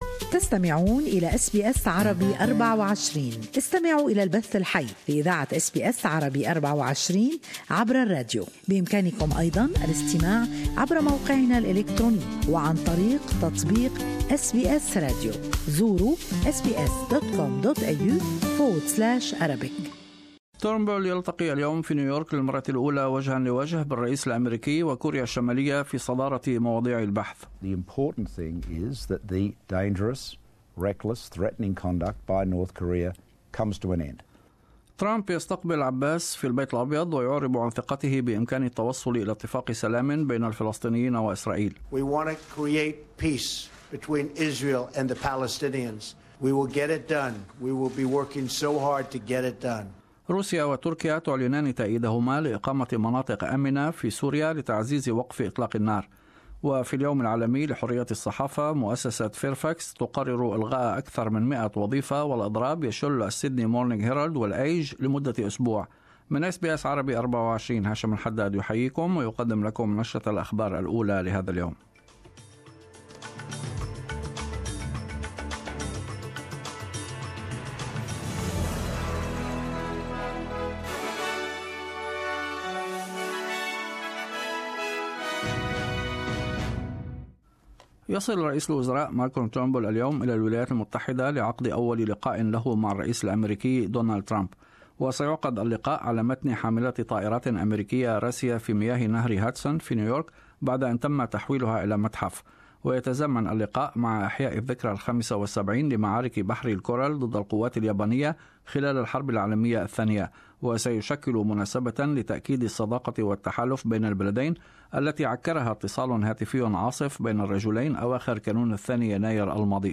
.Australian and world news in morning news bulletin.